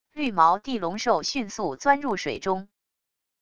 绿毛地龙兽迅速钻入水中wav音频